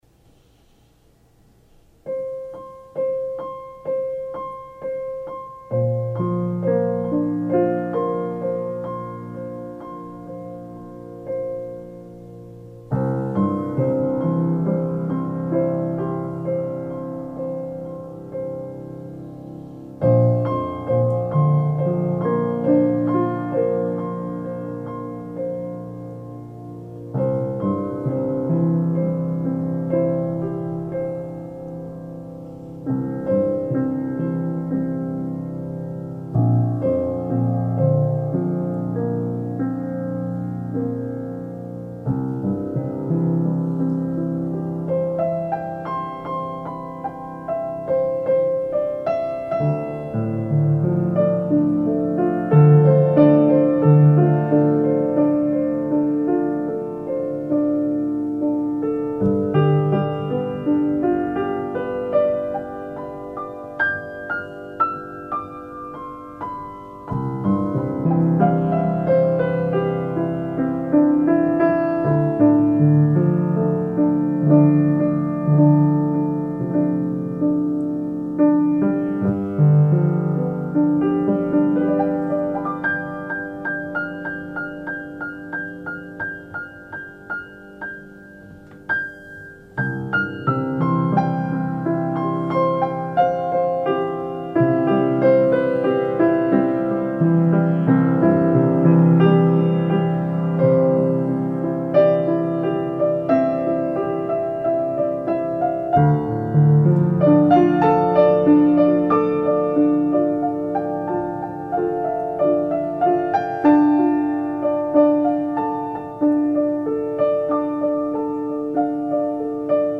The frequencies, 444hz and 528hz may be new to some of you.